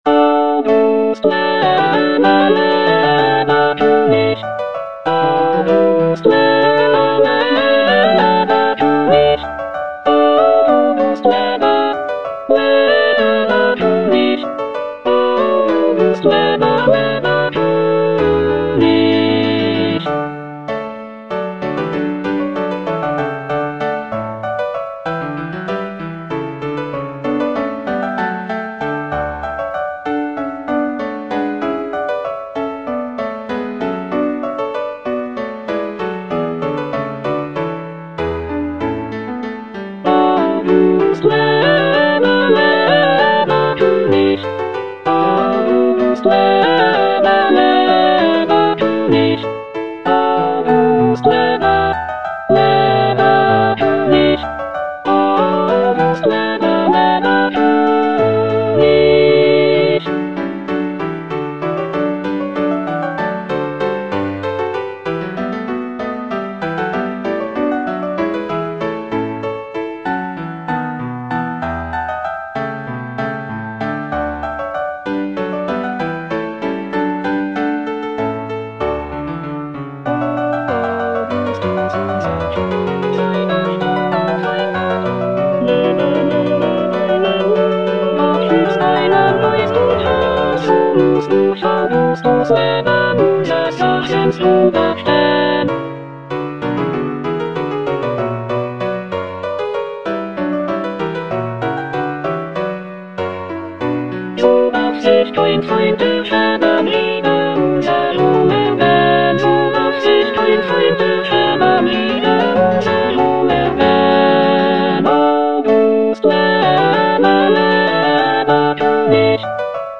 Choralplayer playing Cantata
It is a festive and celebratory work featuring lively trumpet fanfares and joyful choruses.